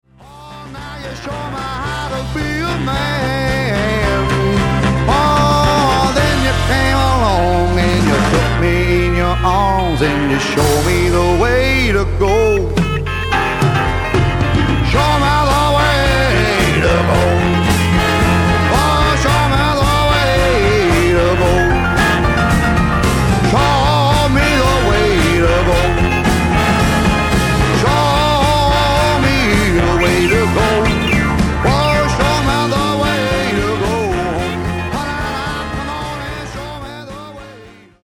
ヴォーカル、12弦ギター
ベース
パーカッション
ピアノ